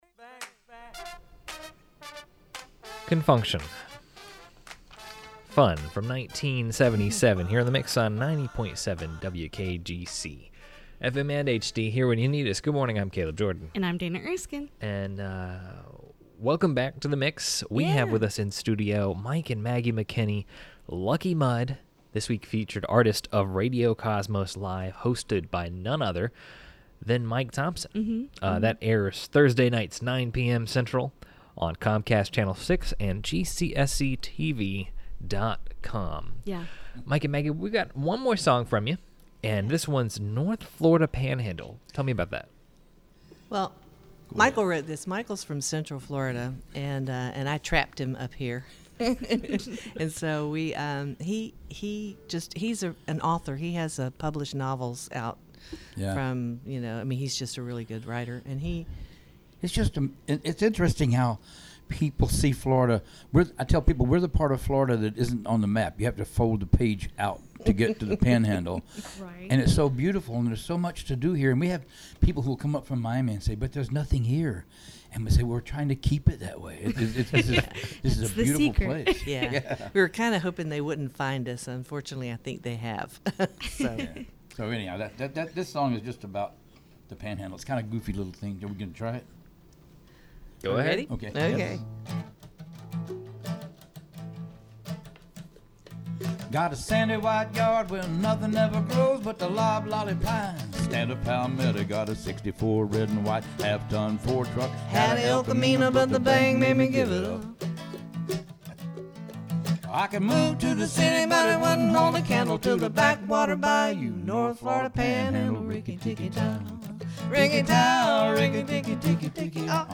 They perform their song, “North Florida Panhandle”.